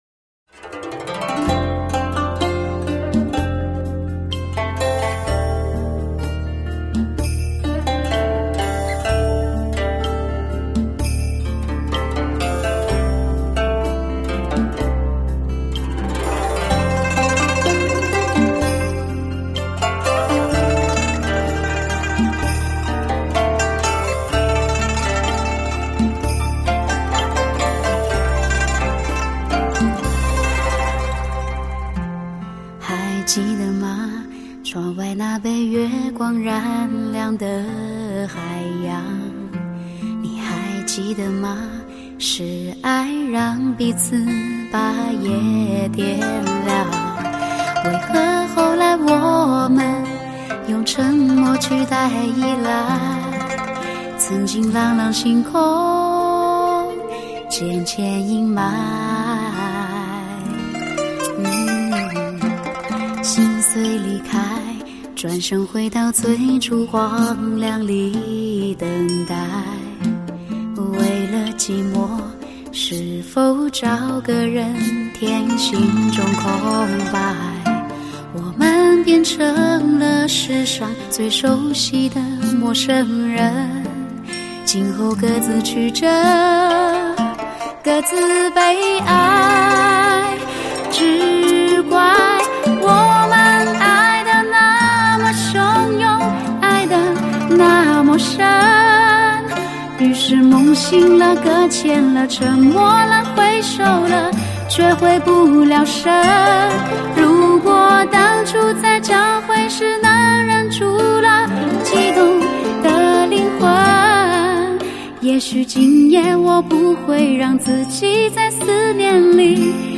这一刻流光飞舞在靡靡女声婉约成诗。